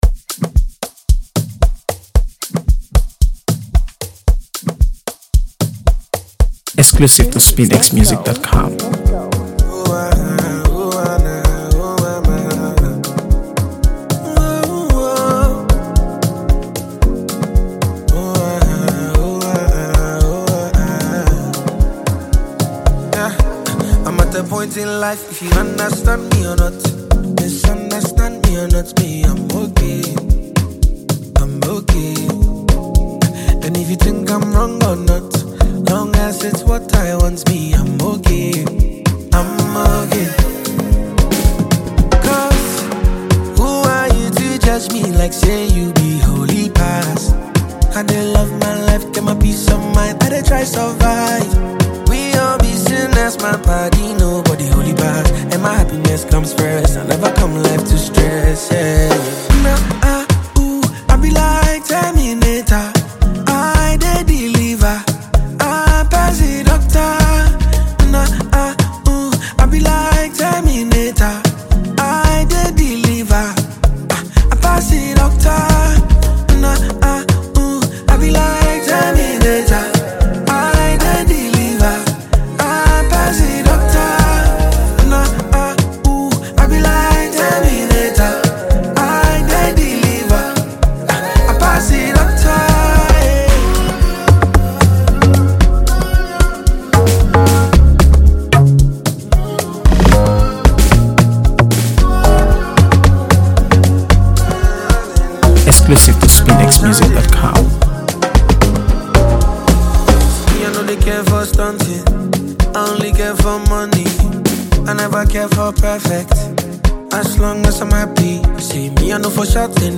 By on Afrobeat